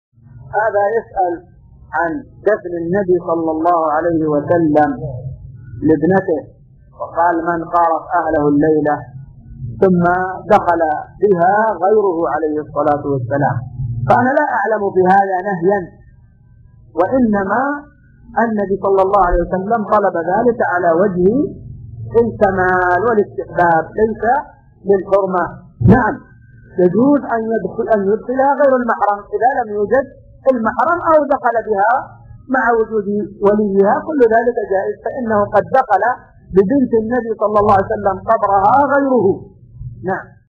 السؤال مقتطف من شرح كتاب الصيام من زاد المستقنع .